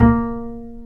Index of /90_sSampleCDs/Roland - Rhythm Section/BS _Jazz Bass/BS _Ac.Fretless